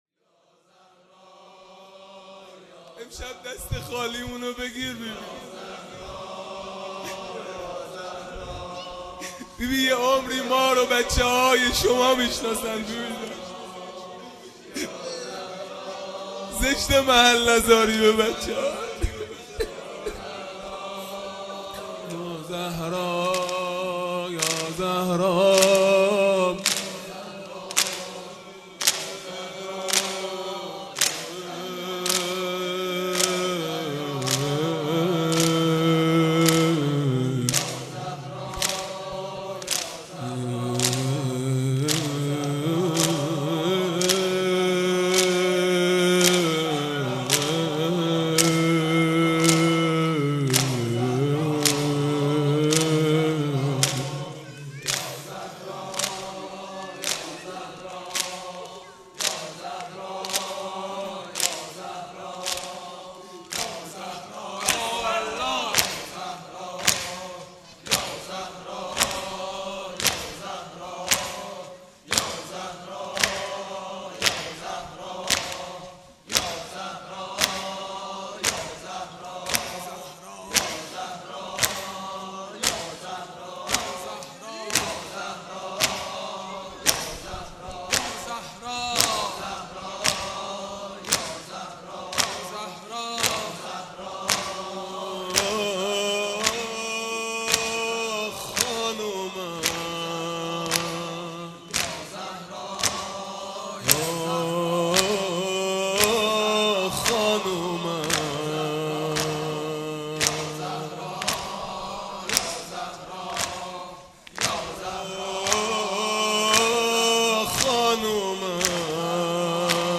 زمینه | چرا دیگه اشک نمیریزی؛چرا دیگه رو نمیگیری
فاطمیه دوم(شب سوم)